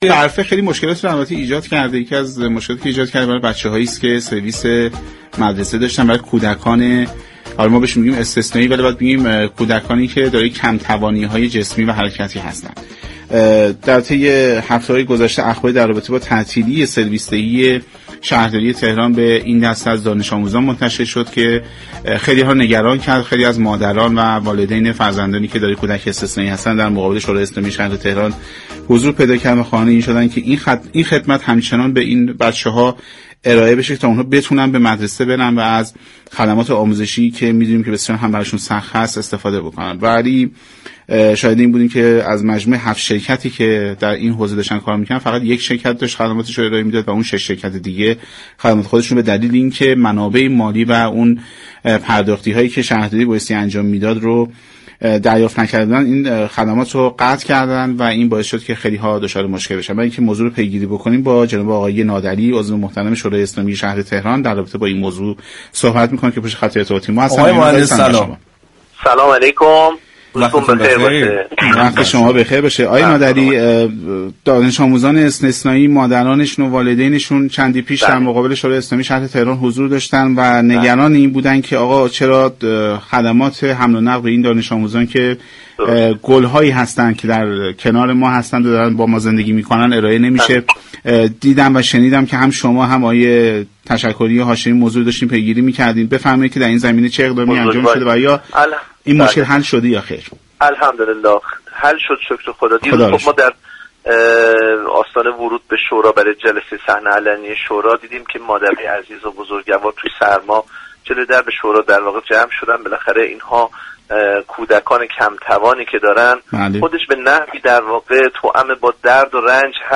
نادعلی عضو شورای اسلامی شهر تهران با حضور در برنامه سعادت اباد رادیو تهران با بیان اینكه بعضی از والدین این دانش‌آموزان، امكان پرداخت هزینه سرویس برای فرزندان خود را ندارند، گفت: بر همین اساس، در سال‌های گذشته مبالغی مشخص شده بود، تا بابت سرویس ایاب و ذهاب دانش‌آموزان مدارس استثنایی پرداخت شود كه شاهد عقب‌ماندگی این دانش‌آموزان از فرآیند آموزش نباشیم.